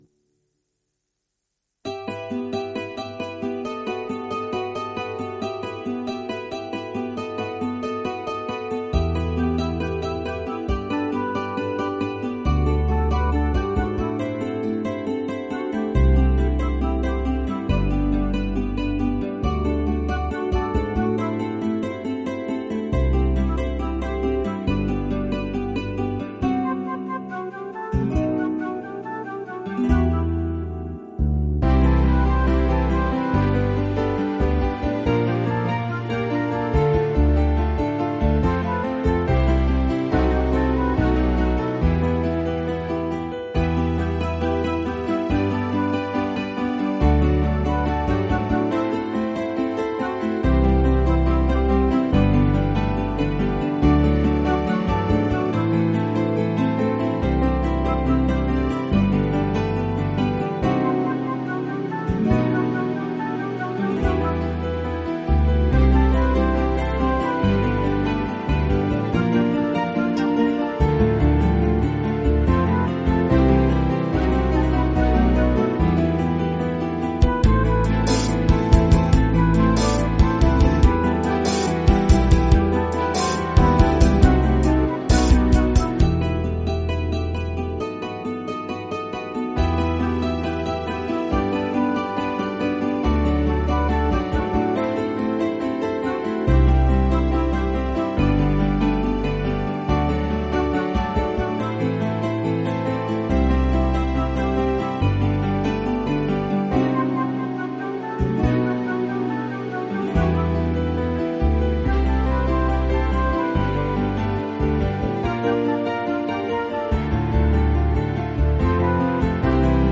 multi-track instrumentale versie